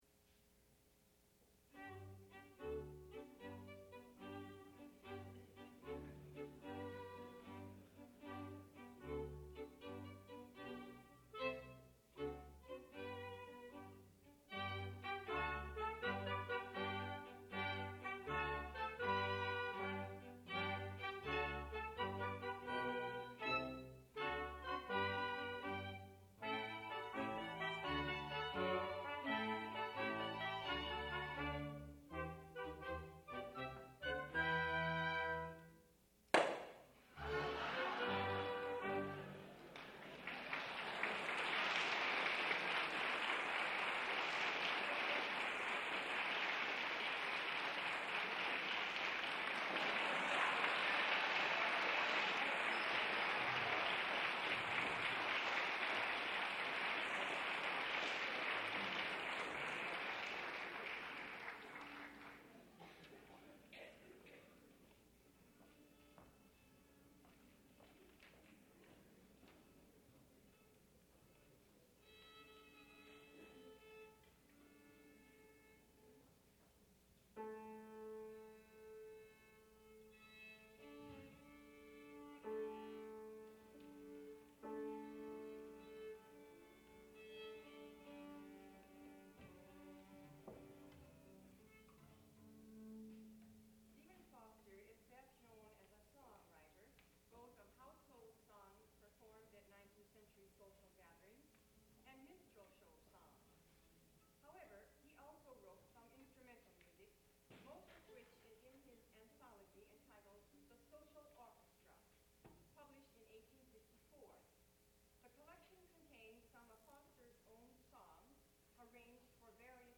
sound recording-musical
classical music
Members of The Shepherd School Of Music Faculty and Students (performer).